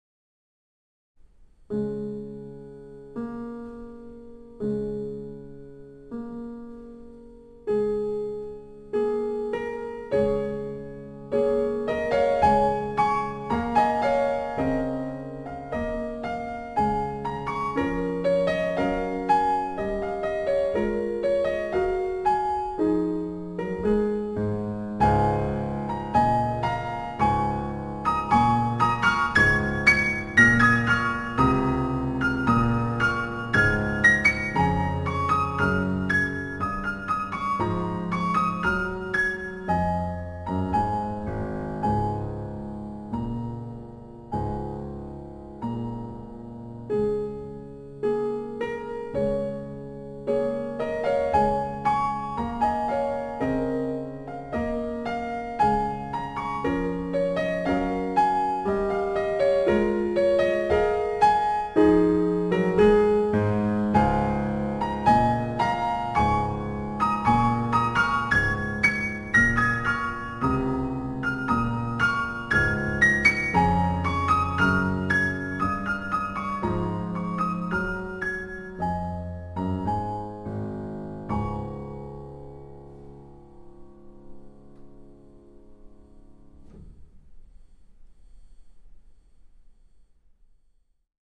Ein Konzertmitschnitt aus dem Wiener Musikverein vom 15. Februar 2022.